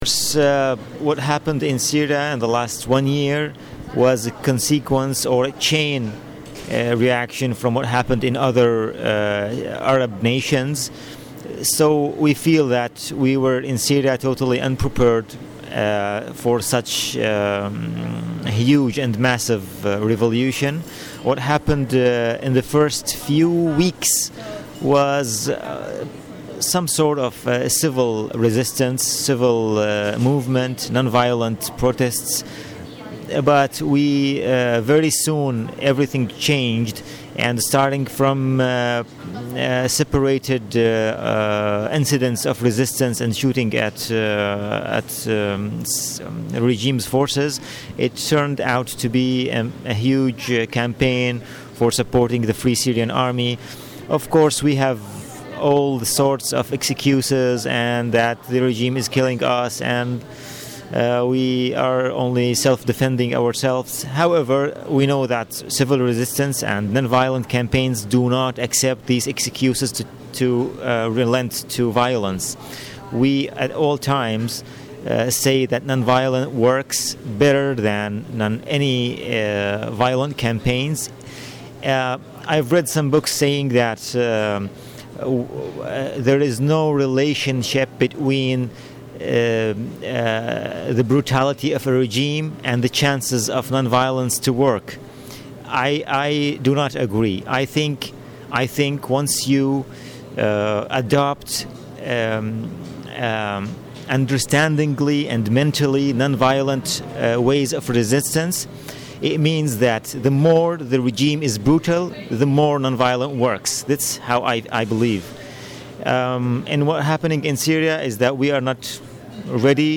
In the following short interview